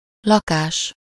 Ääntäminen
Synonyymit loyer maison meublé bauge Ääntäminen France: IPA: /a.paʁ.tə.mɑ̃/ Haettu sana löytyi näillä lähdekielillä: ranska Käännös Ääninäyte 1. bérház 2. lakás Suku: m .